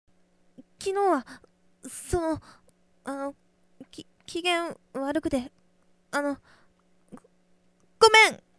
ネット声優に50のお題はこちらお借りしました